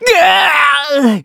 Kibera-Vox_Damage_kr_04.wav